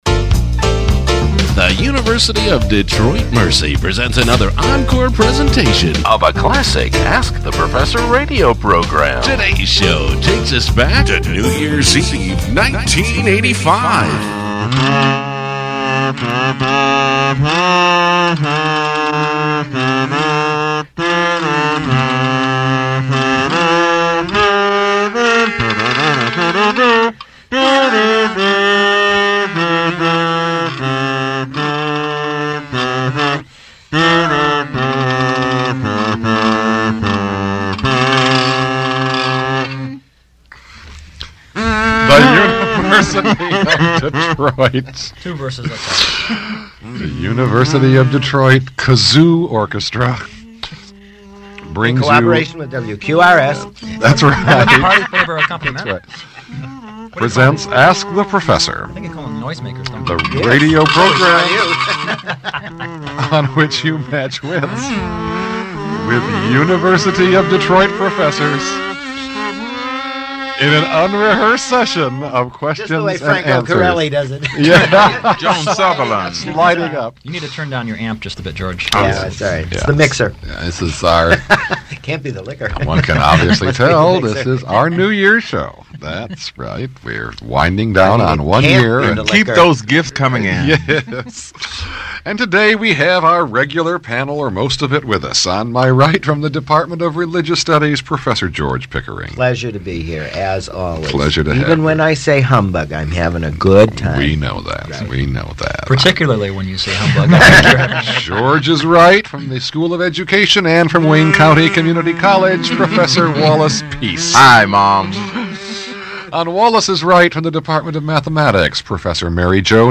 University of Detroit Mercy's broadcast quiz show